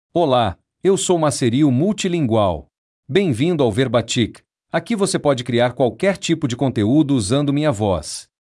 Macerio MultilingualMale Portuguese AI voice
Macerio Multilingual is a male AI voice for Portuguese (Brazil).
Voice sample
Listen to Macerio Multilingual's male Portuguese voice.
Macerio Multilingual delivers clear pronunciation with authentic Brazil Portuguese intonation, making your content sound professionally produced.